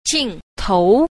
9. 鏡頭 – jìngtóu – kính đầu (ống kính)